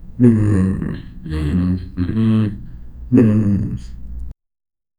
A nostalgic, immersive ambiance that feels real—like a memory coming back to life. 0:10 Distant artillery, soft wind, crows cawing, faint murmurs of soldiers. 0:15 Low murmurs of scholars in discussion 0:05
low-murmurs-of-scholars-i-3oidrna7.wav